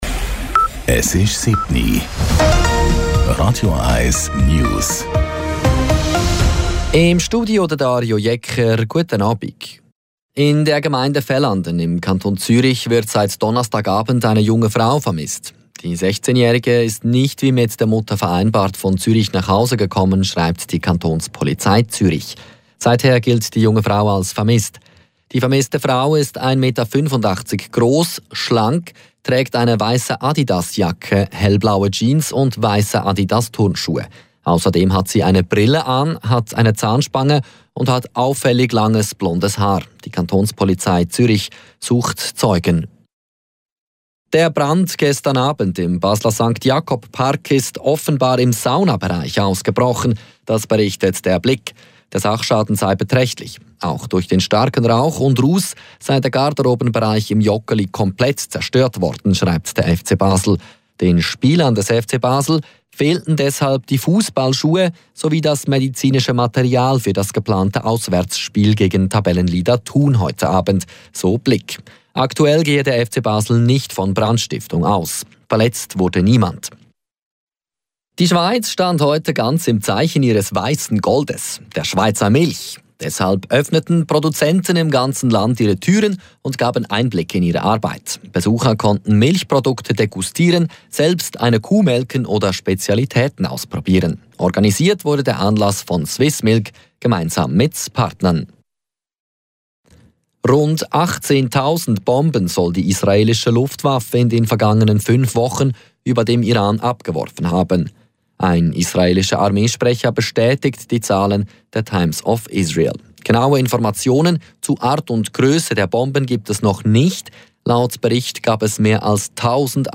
Nachrichten & Politik